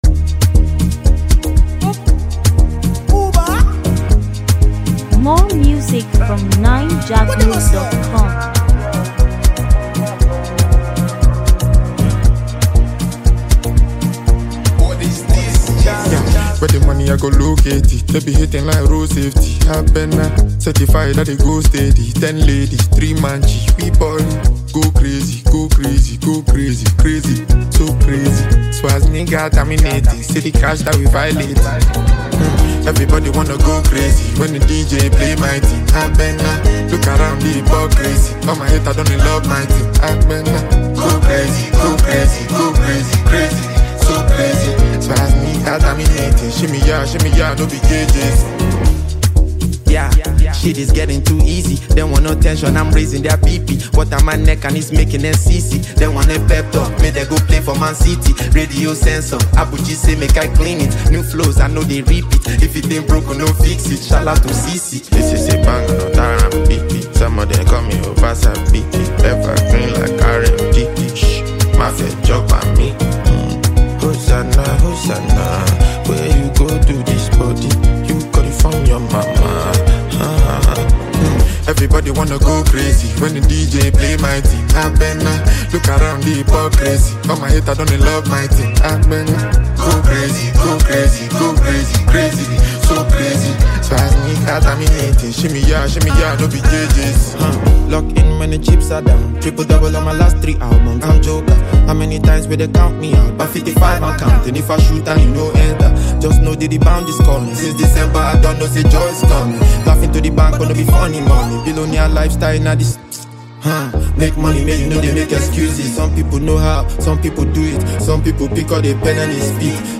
Nigerian singer and rapper